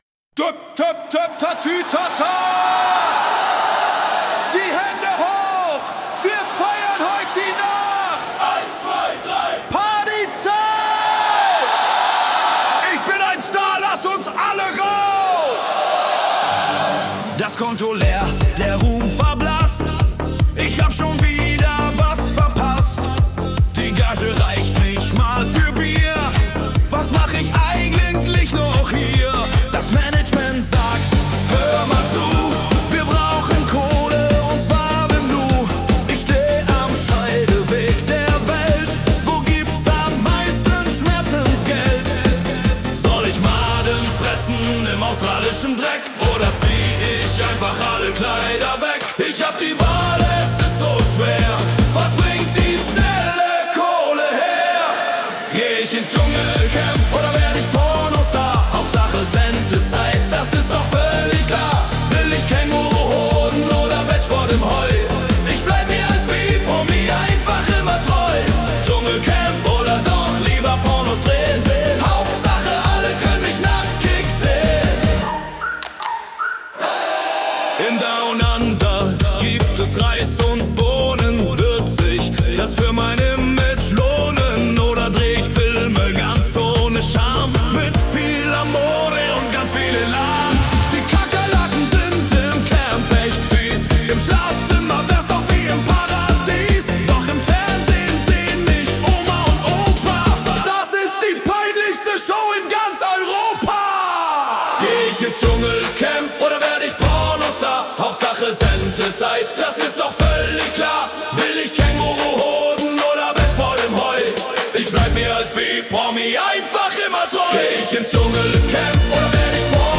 Ballermann Version